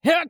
CK蓄力08.wav
CK蓄力08.wav 0:00.00 0:00.30 CK蓄力08.wav WAV · 26 KB · 單聲道 (1ch) 下载文件 本站所有音效均采用 CC0 授权 ，可免费用于商业与个人项目，无需署名。
人声采集素材/男2刺客型/CK蓄力08.wav